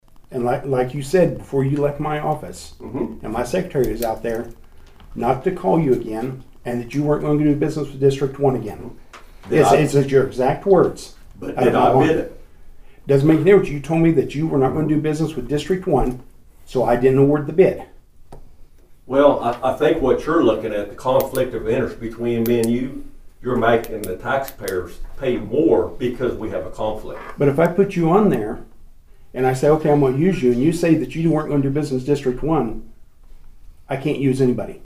The Nowata County Commissioners met on Monday morning at the Nowata County Courthouse Annex.
Chairman Paul Crupper explained why.